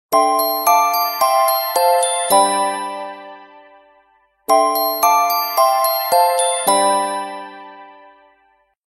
Прикольный рингтон на SMS